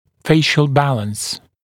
[‘feɪʃl ‘bæləns][‘фэйшл ‘бэлэнс]баланс пропорций лица, гармония лица